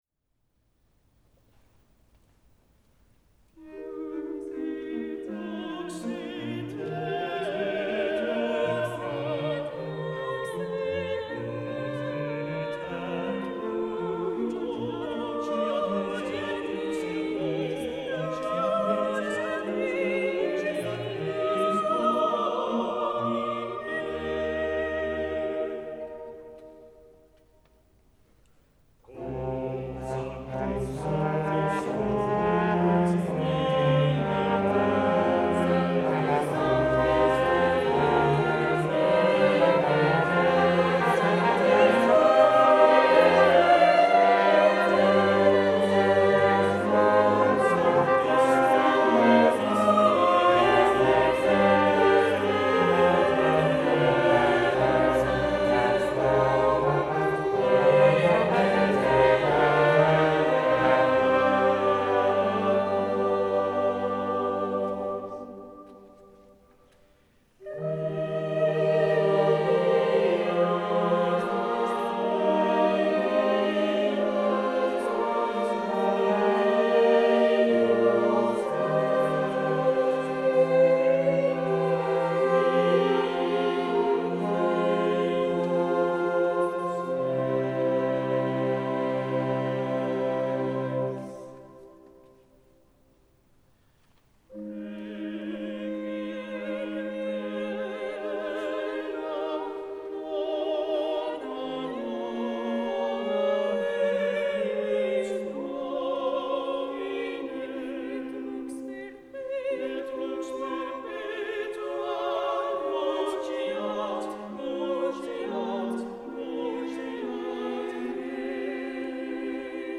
Opnames van uitvoeringen door het SMC
Opname in de Christoffel kathedraal te Roermond op 5 juni 2016